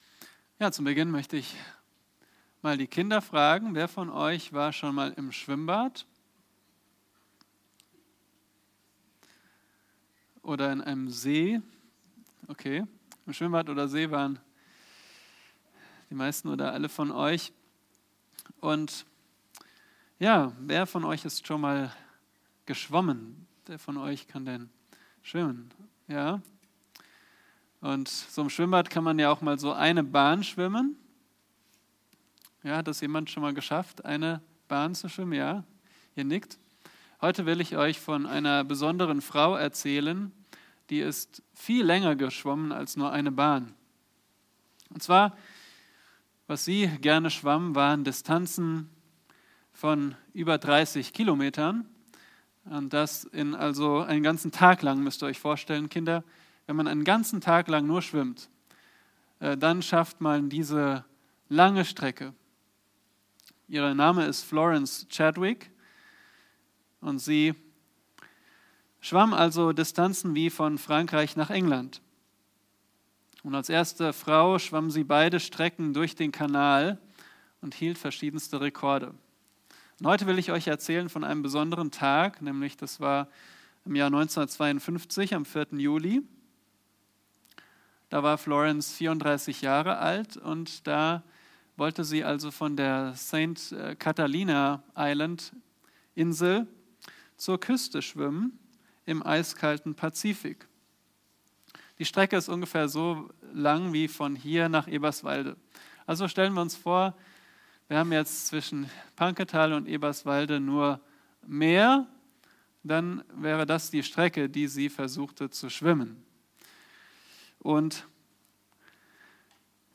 Predigten Übersicht nach Serien - Bibelgemeinde Barnim